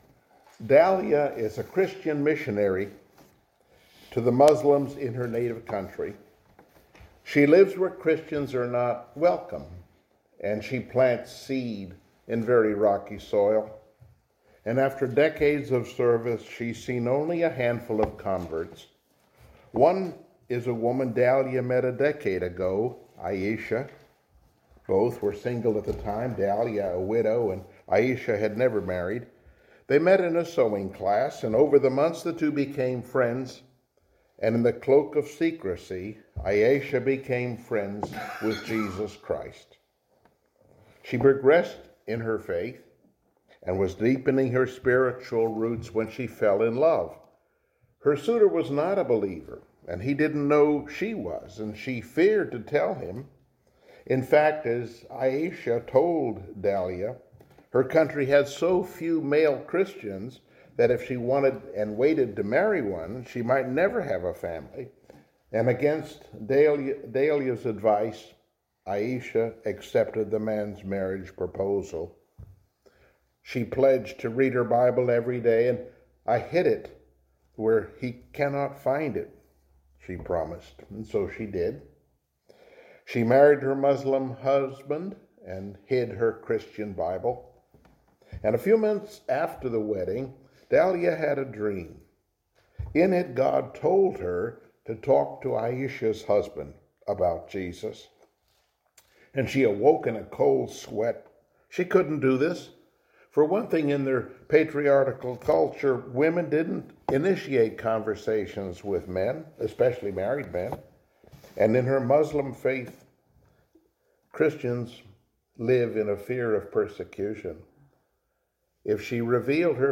Passage: Esther 8 Service Type: Sunday Morning Worship